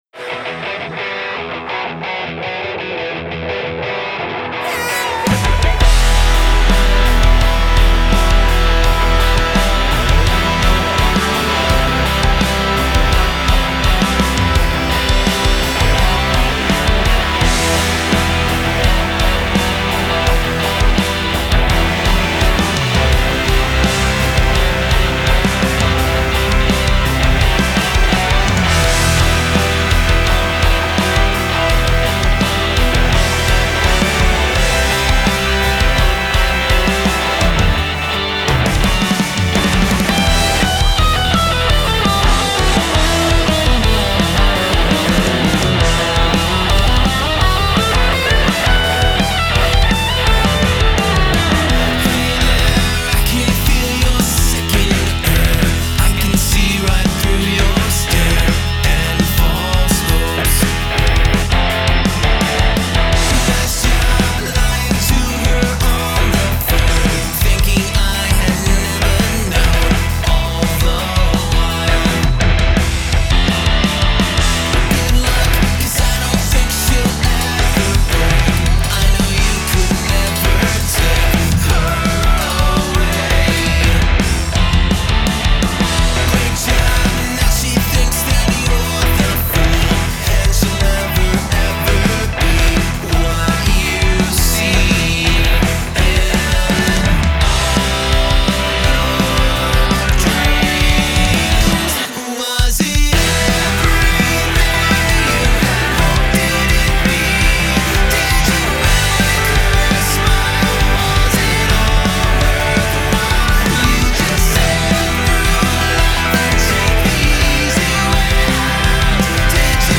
Vocals, Screams, Guitar, Bass, Drums, Keyboards / Synths
Background Vocals